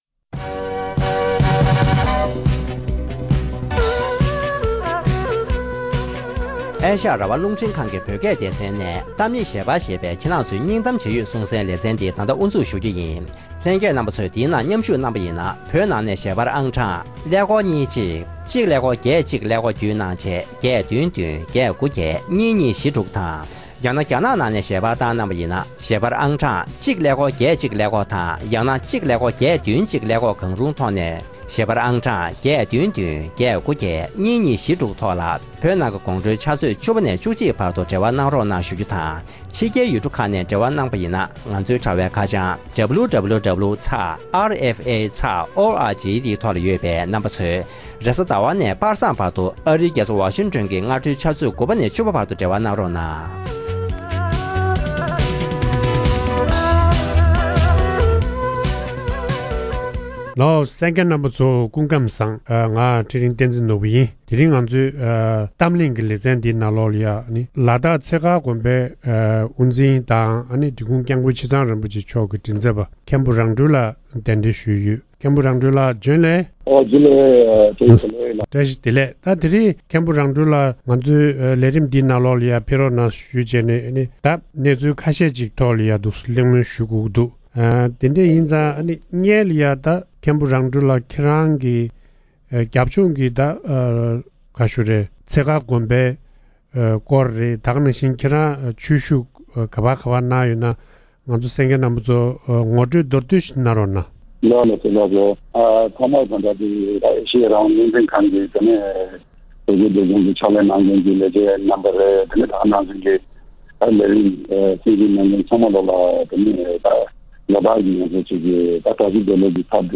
༄༅། །གཏམ་གླེང་ཞལ་པར་གྱི་ལེ་ཚན་འདིའི་ནང་། ཉེ་སྔོན་ལ་དྭགས་ཁུལ་གཞོན་སྐྱེས་ཚོས་དབྱར་ཀའི་འདུ་འཛོམས་སར་ནང་ཆོས་ངོ་སྤྲོད་ཐེངས་གཉིས་པ་གནང་ཡོད་པ་དང་།